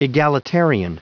Prononciation du mot egalitarian en anglais (fichier audio)
Prononciation du mot : egalitarian